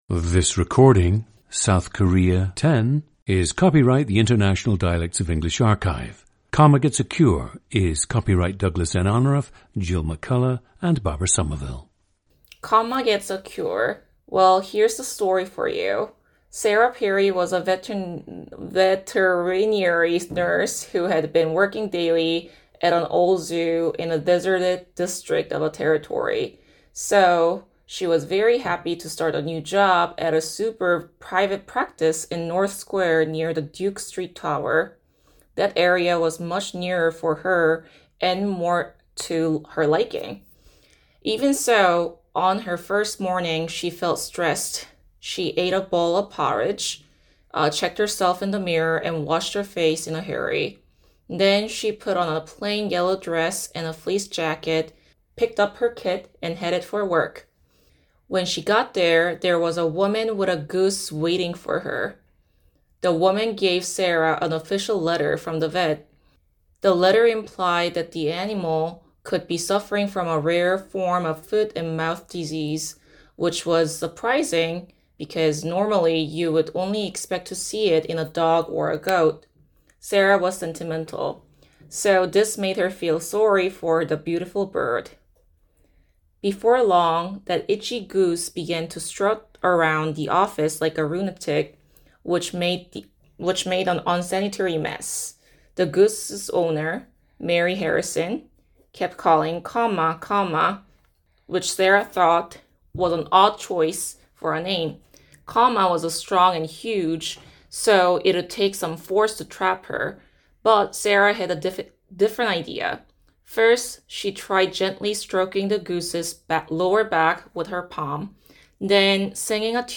Listen to South Korea 10, a 33-year-old woman from Daejeon, South Korea.
GENDER: female
In this regard, she is a good subject to study if one is seeking a mild Korean-American dialect.